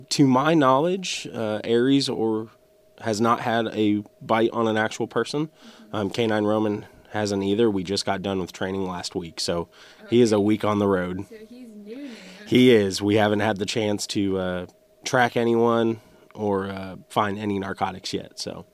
Howell County, MO. – Following the current project of Back the Blue K9 Edition, we had the Howell County Sheriff’s Department come in for an interview to describe the day in the life of a K9, specifically one that has only been on the job for a week.